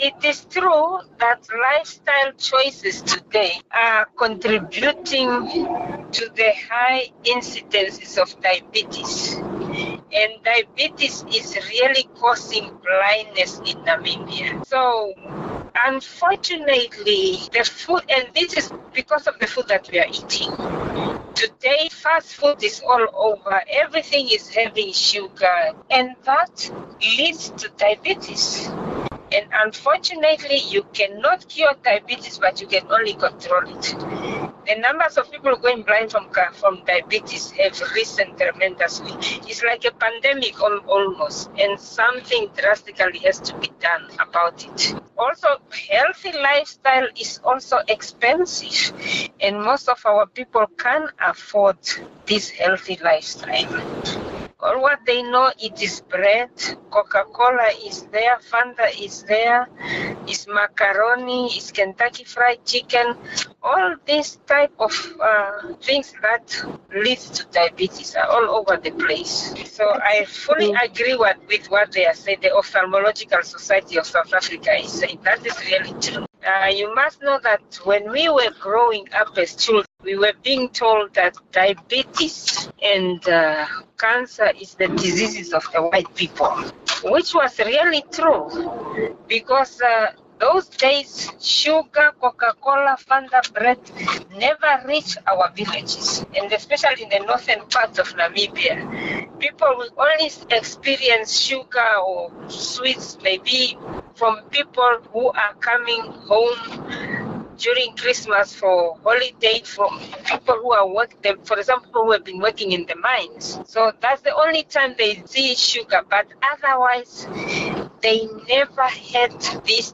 Future Media News Bulletins